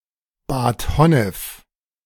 Bad Honnef (German: [baːt ˈhɔnəf]
De-Bad_Honnef.ogg.mp3